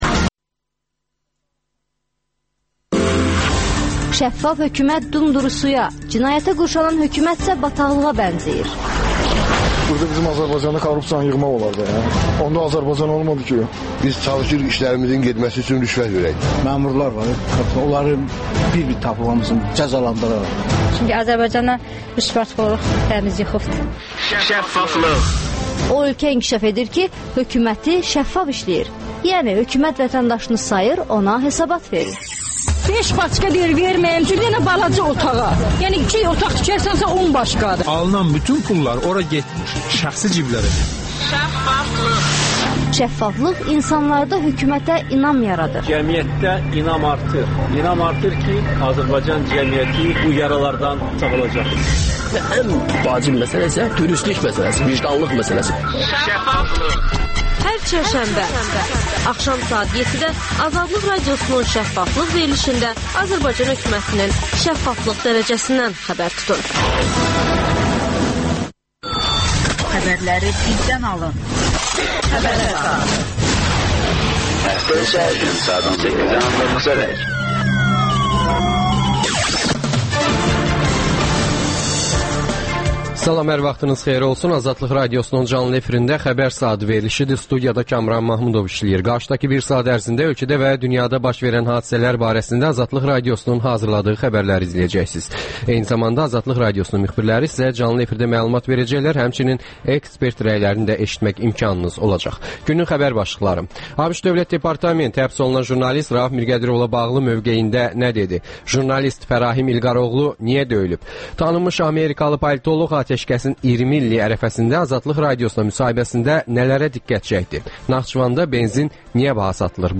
AzadlıqRadiosunun müxbirləri ölkə və dünyada baş verən bu və başqa olaylardan canlı efirdə söz açırlar.